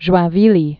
(zhoiɴ-vēlē)